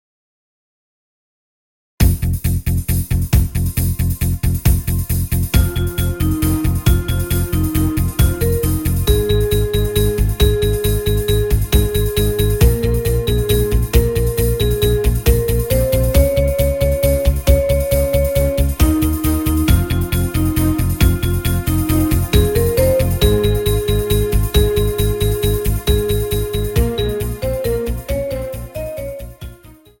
Instrumental Solos Trumpet